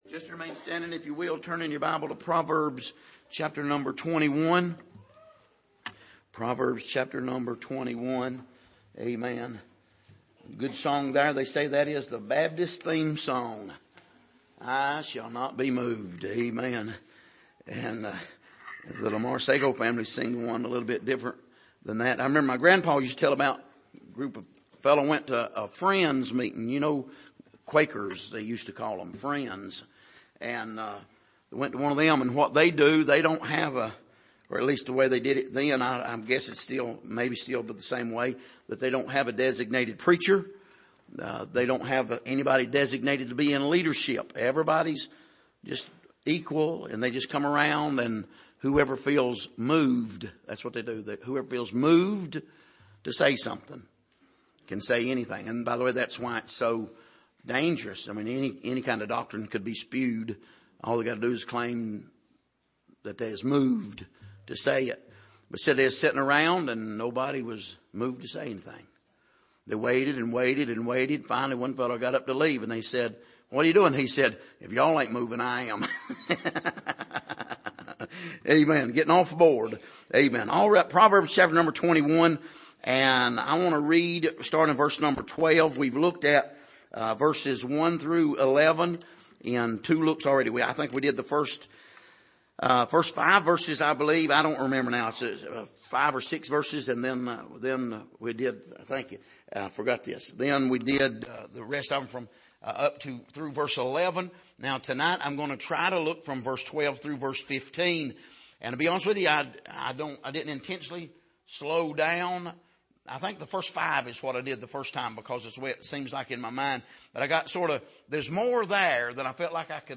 Passage: Proverbs 21:12-15 Service: Sunday Evening